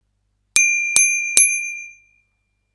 bell3.wav